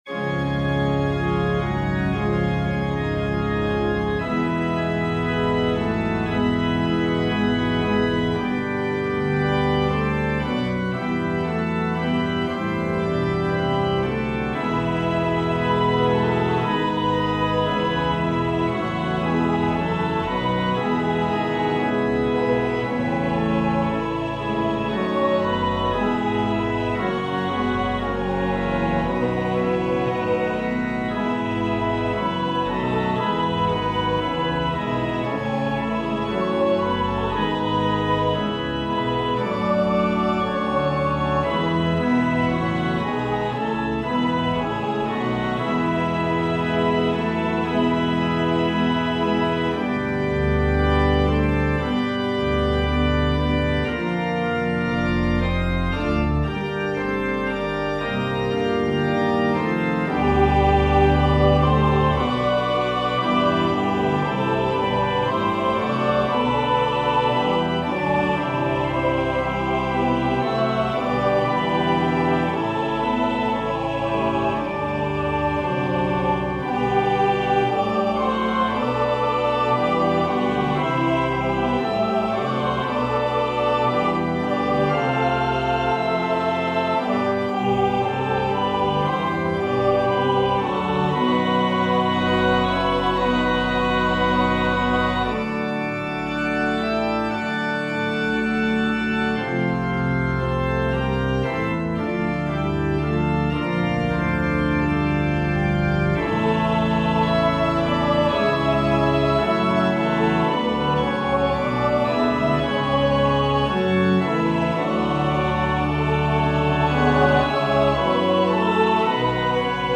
SATB , SSATB , Organ/Organ Accompaniment